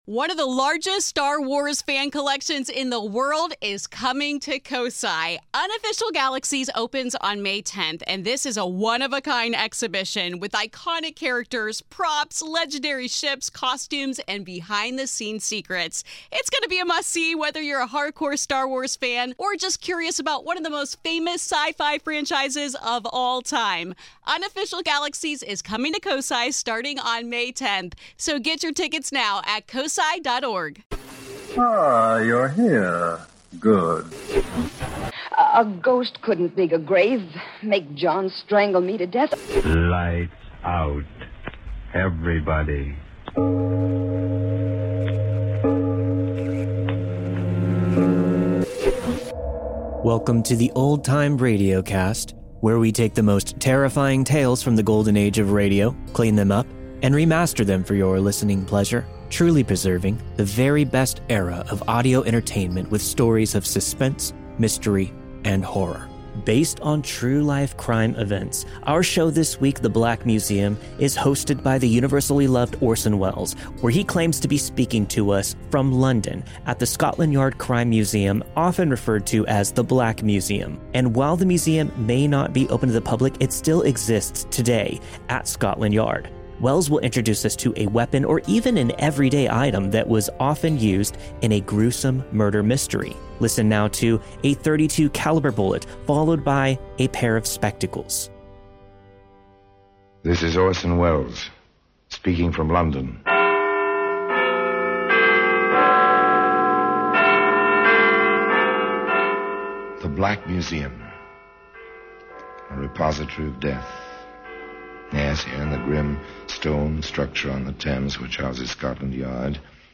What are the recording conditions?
On this week's episode of the Old Time Radiocast we present you with two stories from the classic radio program The Black Museum.